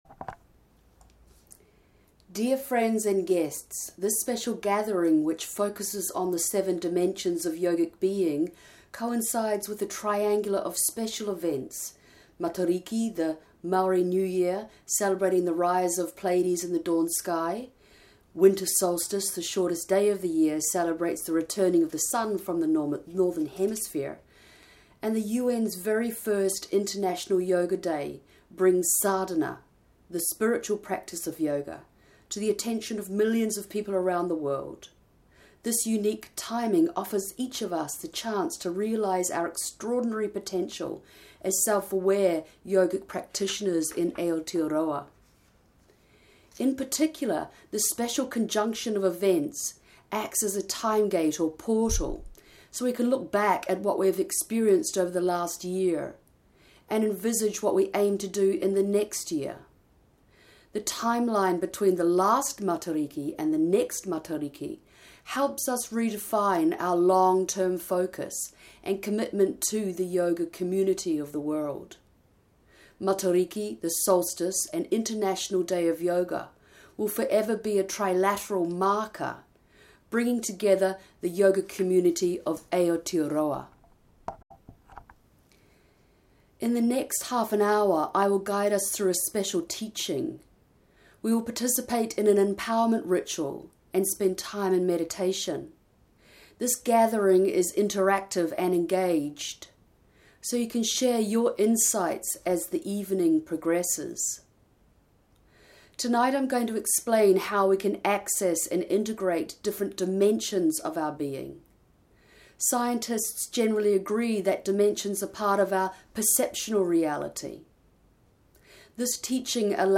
International Yoga Day – EMPOWERMENT MEDITATION